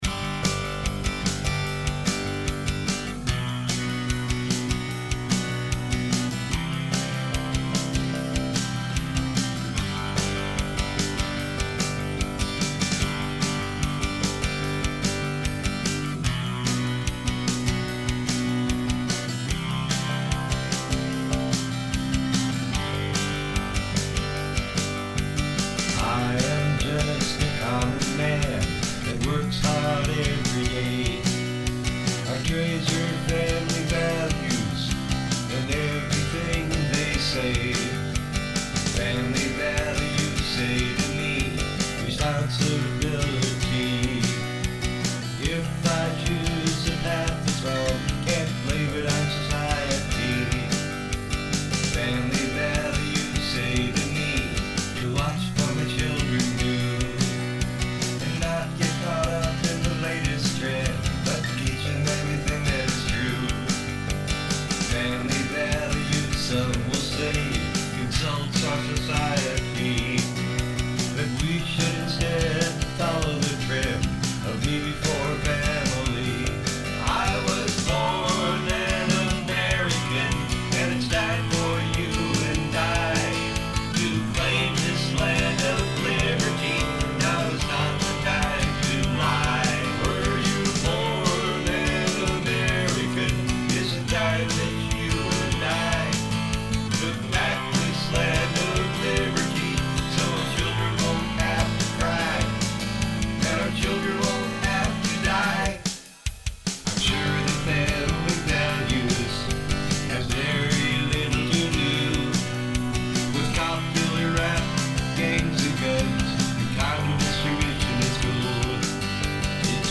Accoustic Guitar
Drums, Guitar and Bass